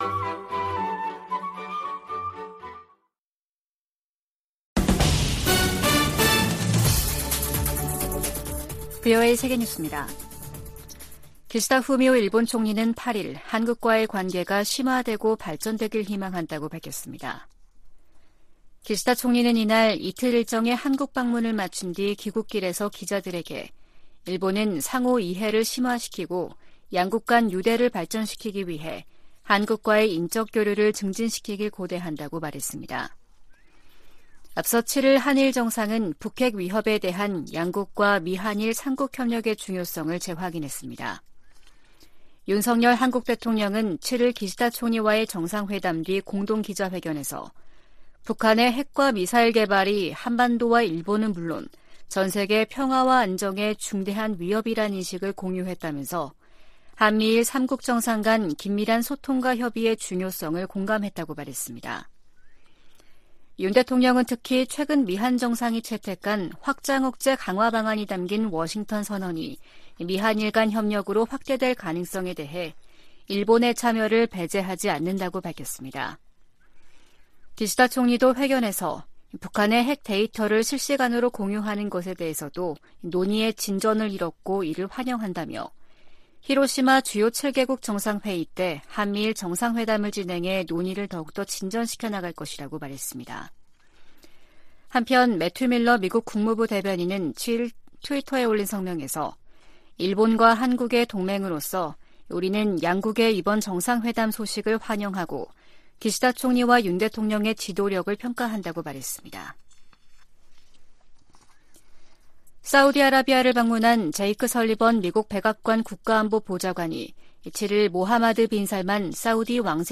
VOA 한국어 아침 뉴스 프로그램 '워싱턴 뉴스 광장' 2023년 5월 9일 방송입니다. 윤석열 한국 대통령과 기시다 후미오 일본 총리가 7일 북한이 제기하는 위협을 거론하며 미한일 3국 공조의 중요성을 강조했습니다.